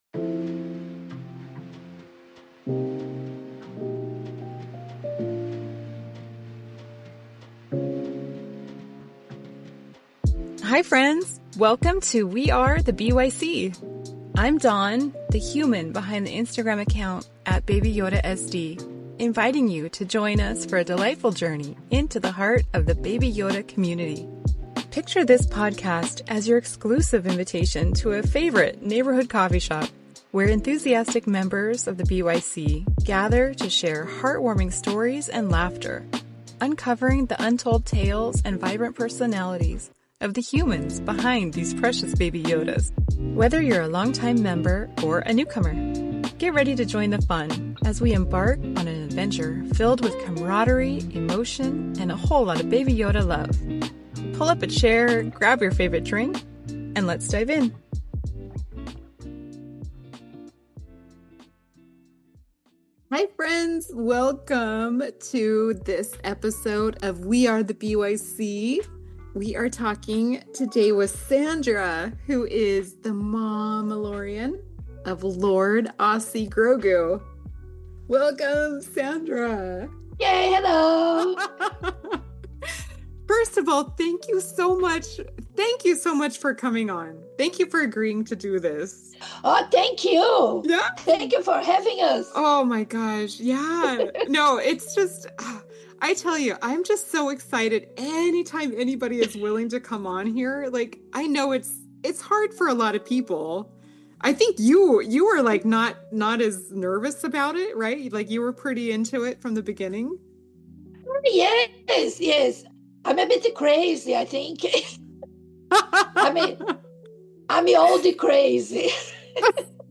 Introductory episode.“We are the BYC” will showcase the voices of the BYC.